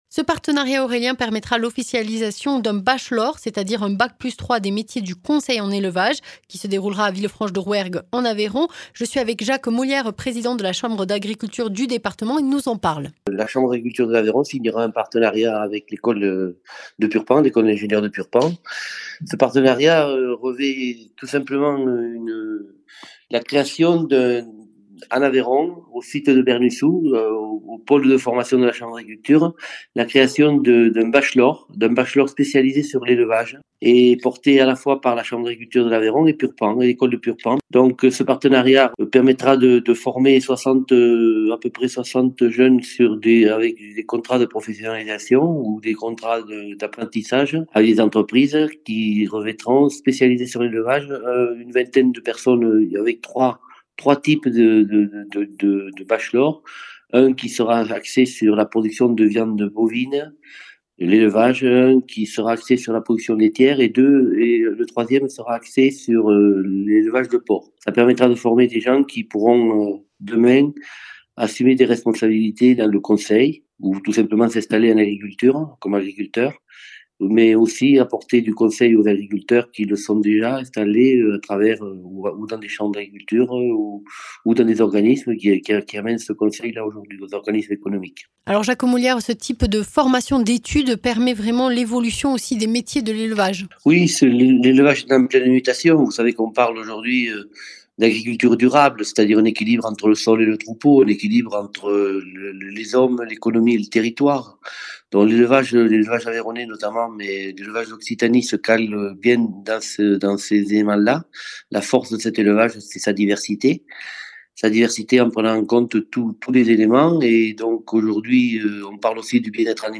Interviews
Invité(s) : Jacques Molières, président de la chambre d’agriculture de l’Aveyron